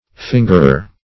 Fingerer \Fin"ger*er\, n. One who fingers; a pilferer.